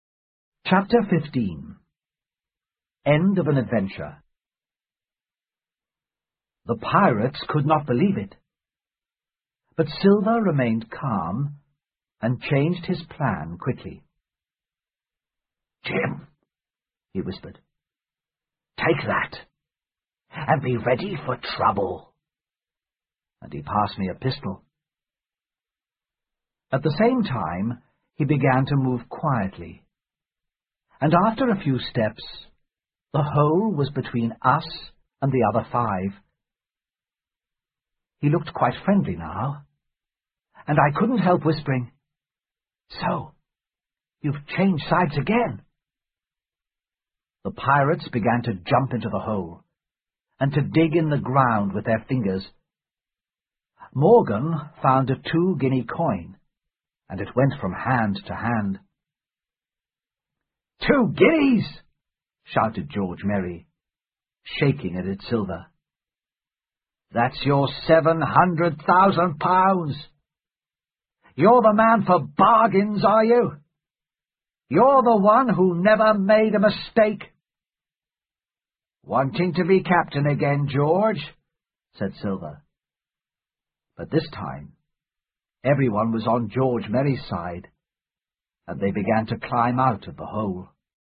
在线英语听力室《金银岛》第十四章 寻宝结局(1)的听力文件下载,《金银岛》中英双语有声读物附MP3下载